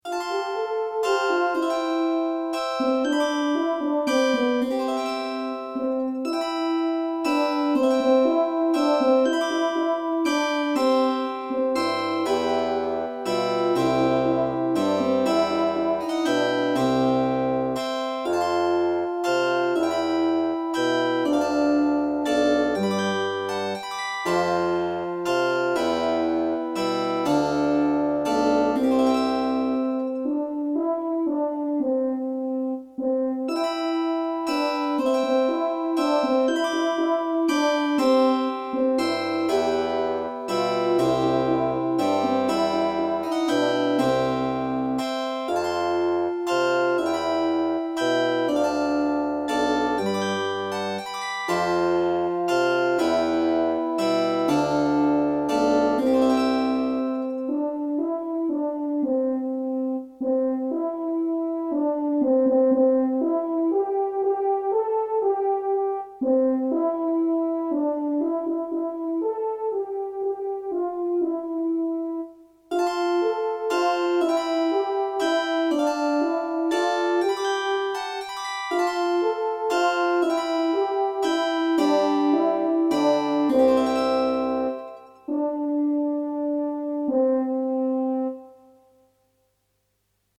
Noël hollandais
Noël. En do majeur, avec un accompagnement de clavecin, de cor et basson.
(sans flûte)